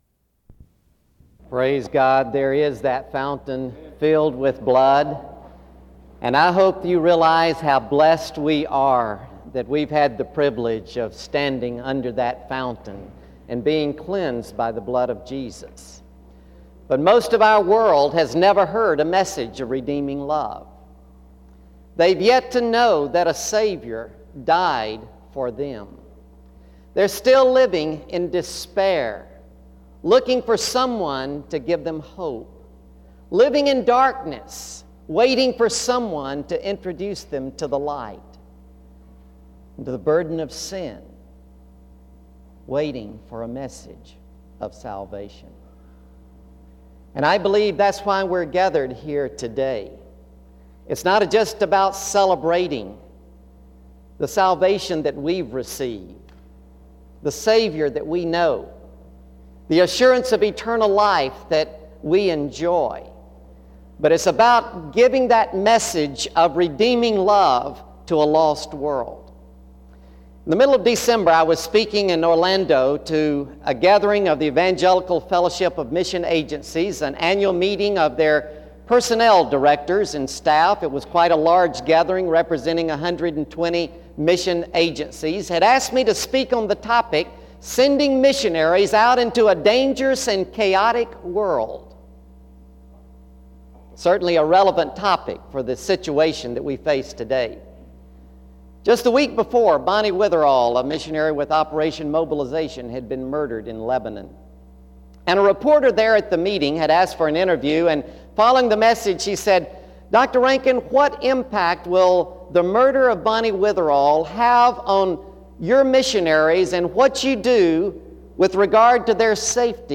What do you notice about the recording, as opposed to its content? SEBTS Chapel and Special Event Recordings - 2000s